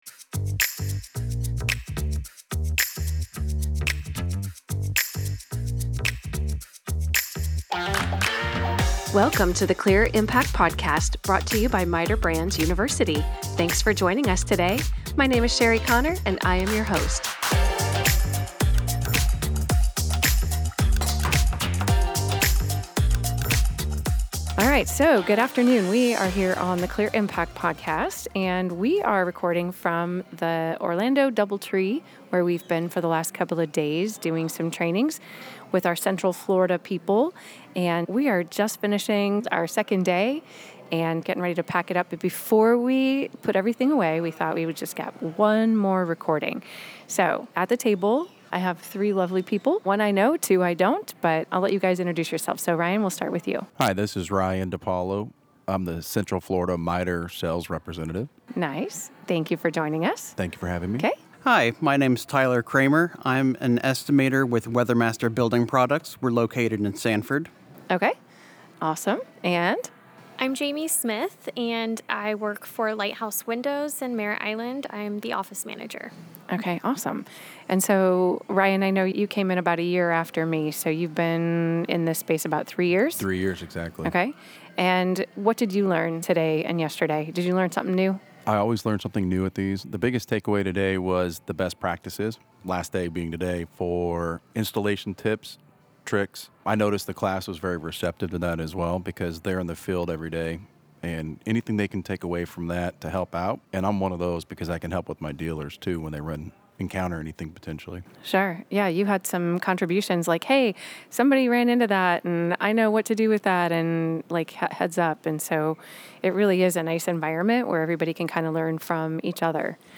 Interacting with customers at a training event is one of our favorite things!